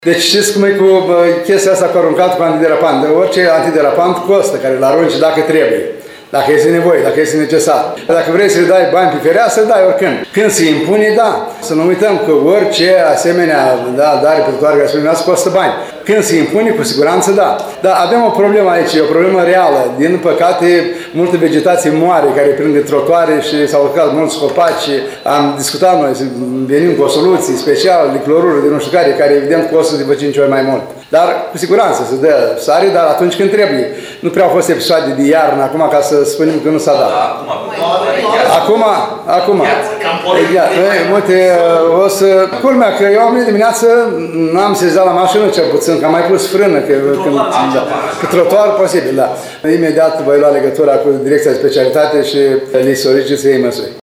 La conferința de presă din această dimineață, primarul ION LUNGU a fost solicitat să spună de ce au intervenit cu întârziere angajații firmei Diasil.